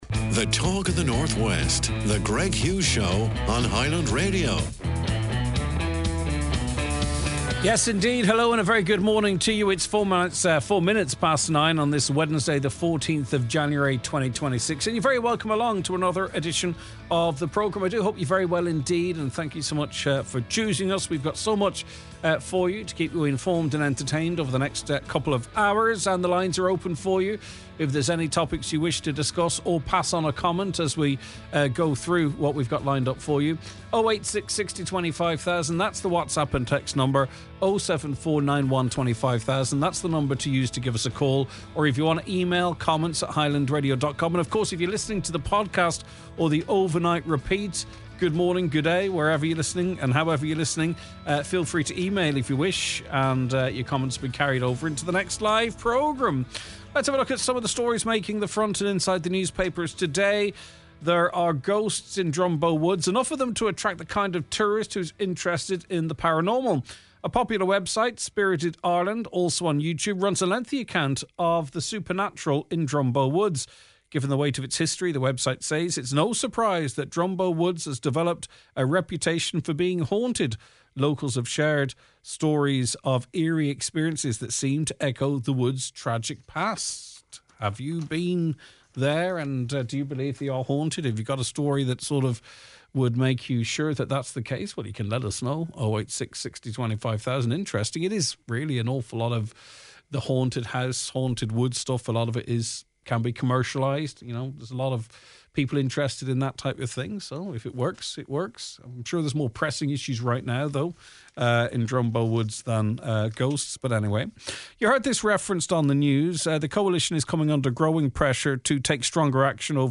Privacy Confrontation in Inishowen: A listener shares her story of demanding a stranger delete unauthorized footage of her child in a local shop.